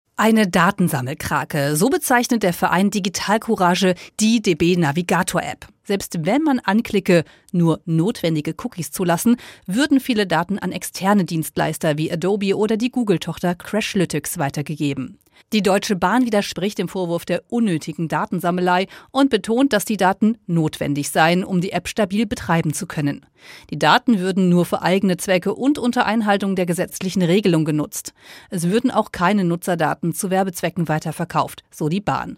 SWR3-Reporterin